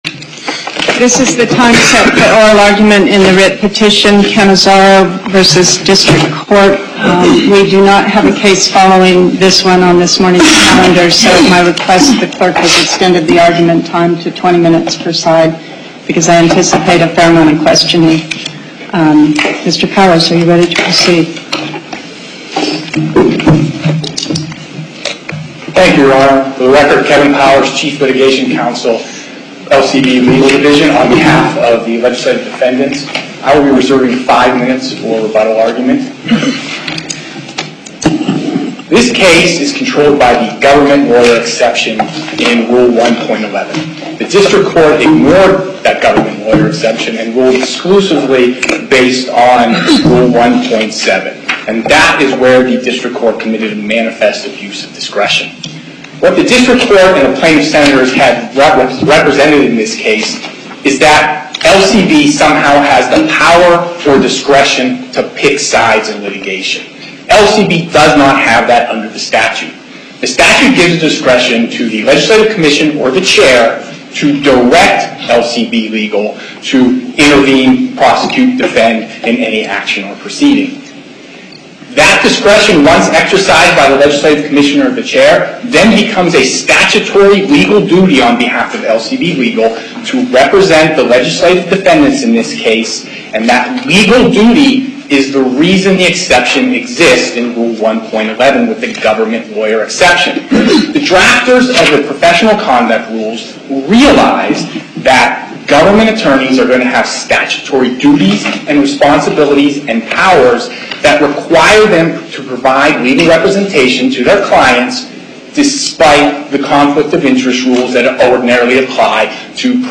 Location: Las Vegas Before the En Banc Court, Chief Justice Pickering Presiding